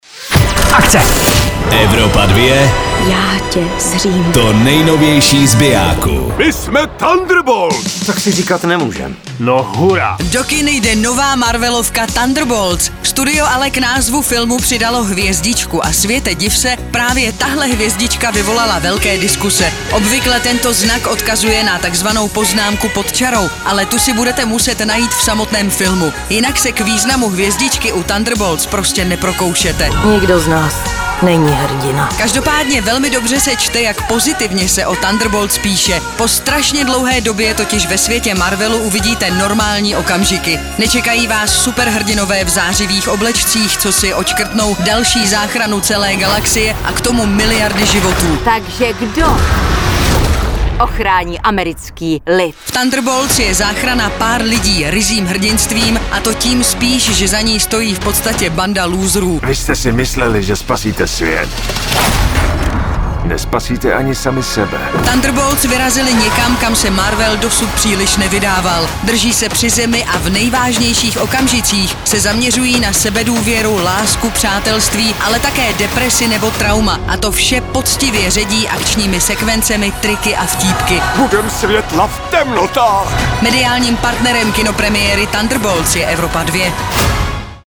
filmový trailer